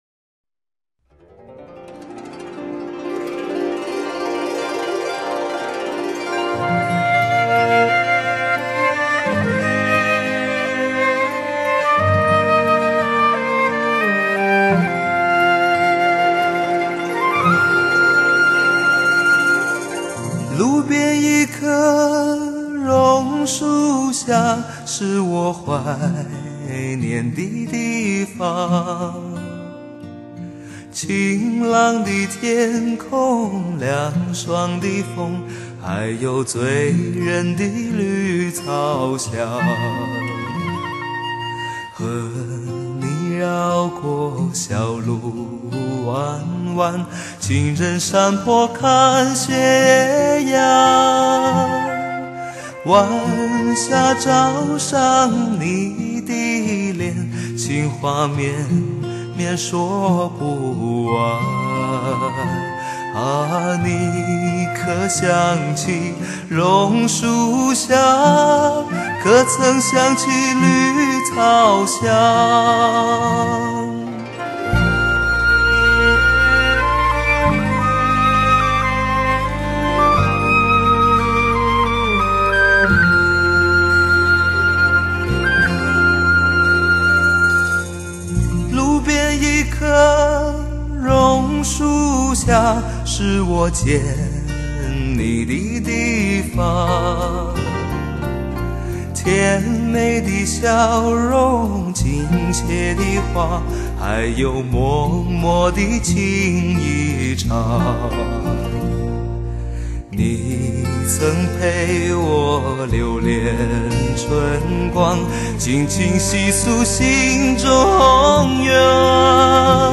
歌很老了，音效却很好